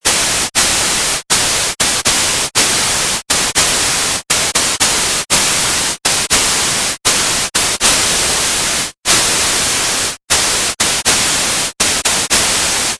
This class shows the rendering of an audio file with white noise.
The noise instrument is different from the sine or triangle (or any other oscillator) because it does not use a wavetable.
The noise instrument continually calculates random sample values as required.